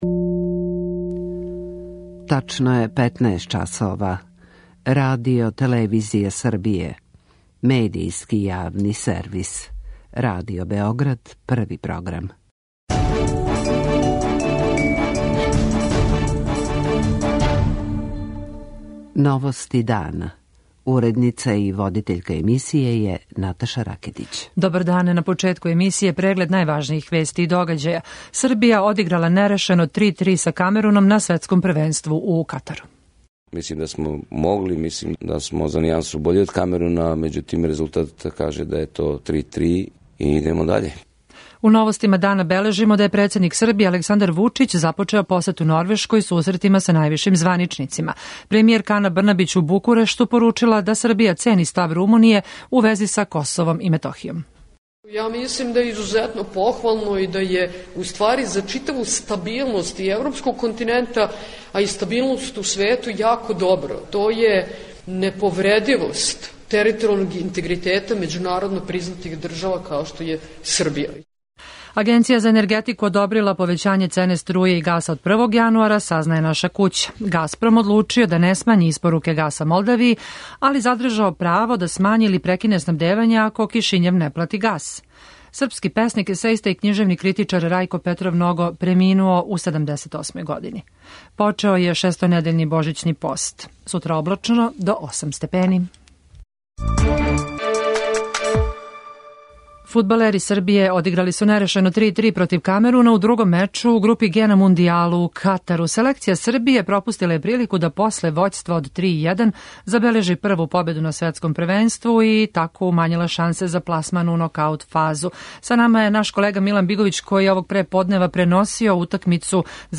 novosti2811.mp3